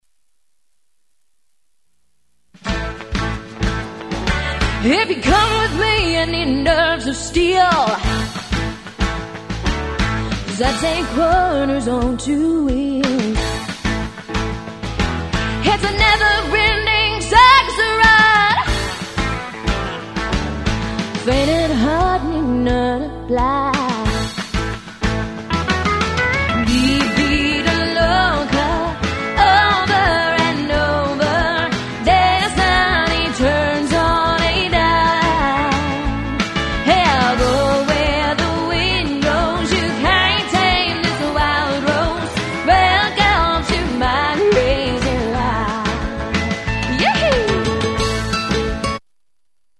C  O  U  N  T  R  Y     C  O  V  E  R  S